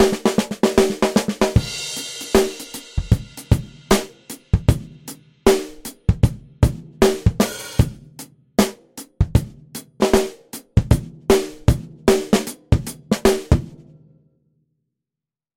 BFD 3.5 是 FXpansion 旗舰级原声鼓虚拟乐器，主打超真实多麦采样 + 深度物理建模 + 专业级混音控制，是影视、摇滚、金属、爵士等风格的顶级鼓制作工具BFD Drums。